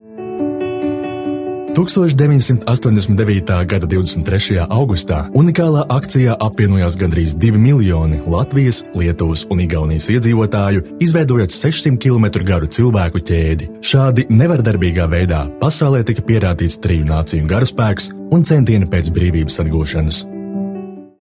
Sprecher, Werbesprecher
Male